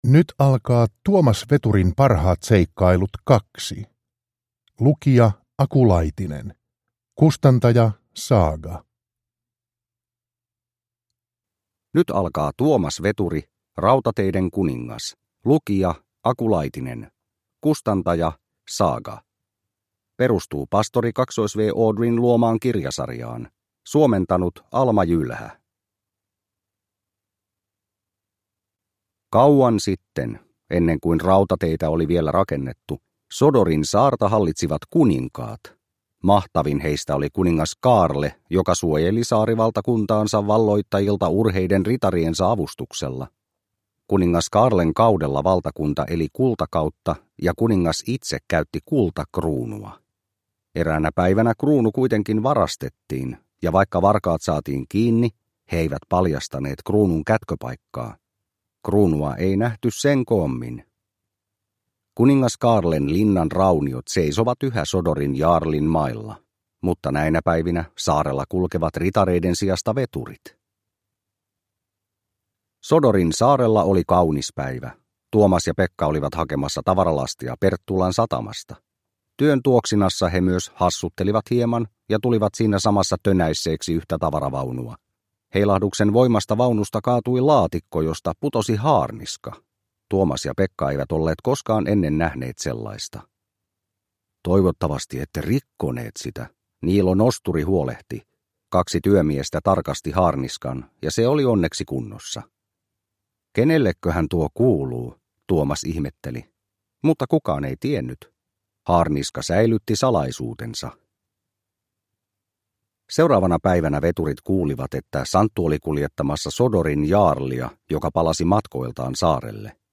Tuomas Veturin parhaat seikkailut 2 – Ljudbok